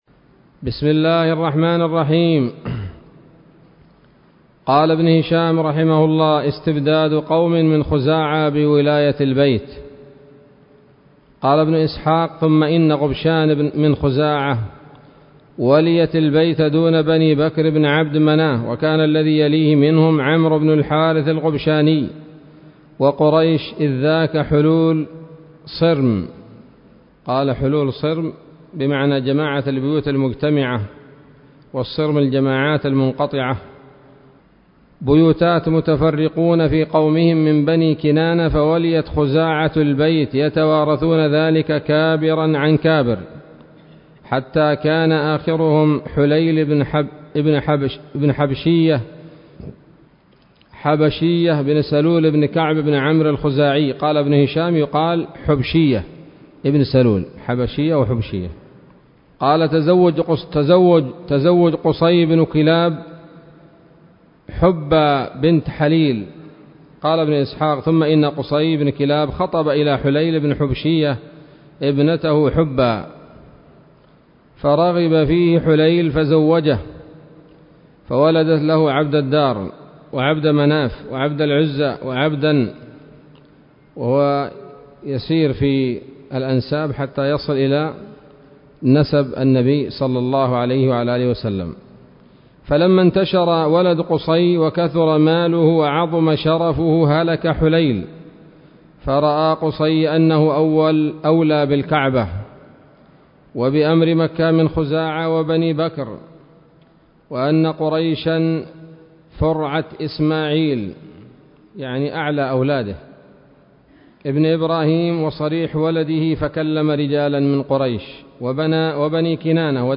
الدرس العاشر من التعليق على كتاب السيرة النبوية لابن هشام